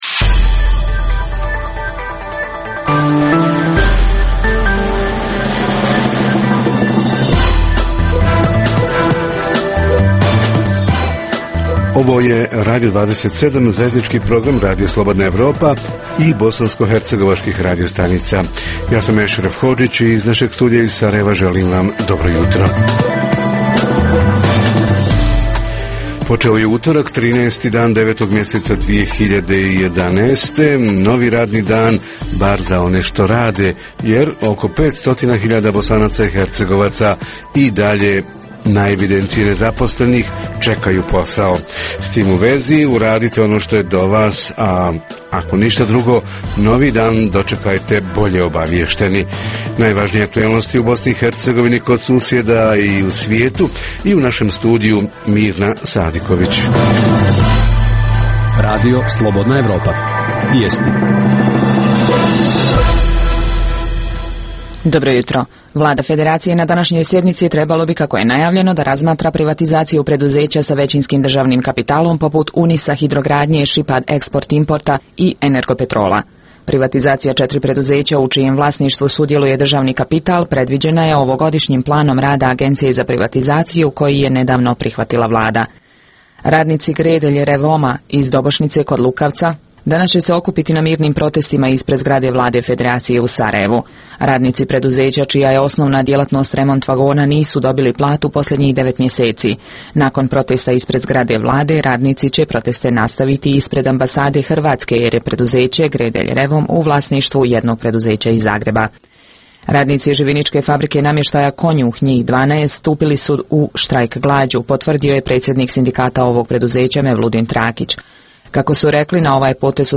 Od 1995. godine oko 100 hiljada mladih otišlo je iz BiH – danas njih 70 odsto izjavljuje da žele da odu – koji su najčešći uzroci i kako zaustaviti taj proces? Reporteri iz cijele BiH javljaju o najaktuelnijim događajima u njihovim sredinama.
Redovni sadržaji jutarnjeg programa za BiH su i vijesti i muzika.